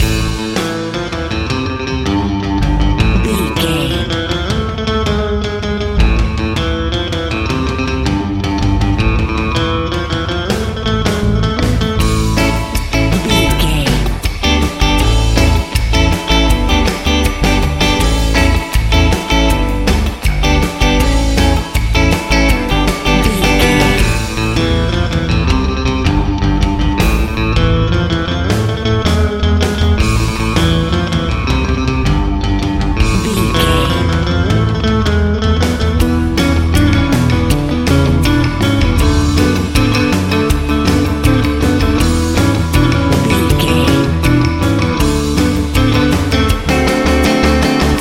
Epic / Action
Fast paced
Aeolian/Minor
groovy
driving
energetic
bass guitar
drums
electric guitar
80s